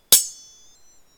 sword_clash.7.ogg